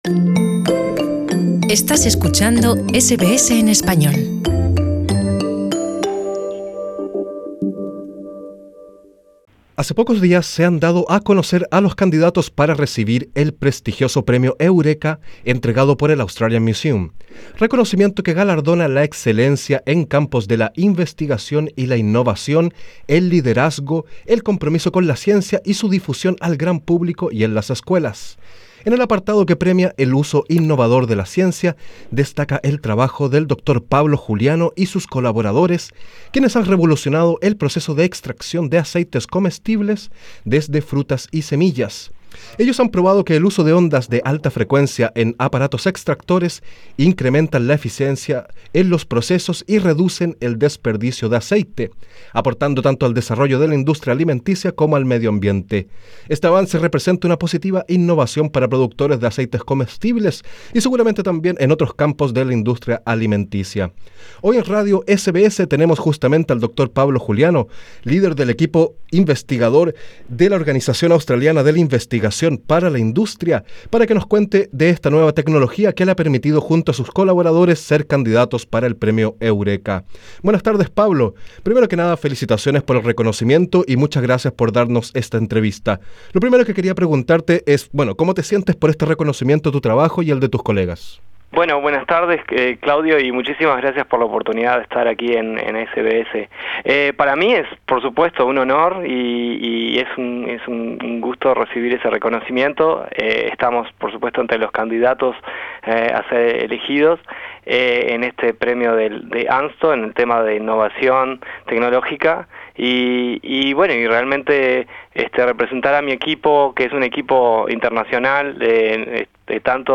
En SBS Radio entrevistamos